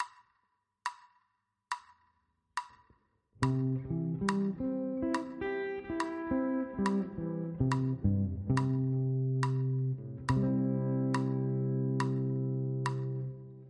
Ex 3 – C-Dur Arpeggio – G-Shape